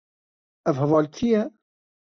Pronounced as (IPA) /hɛˈvɑːl/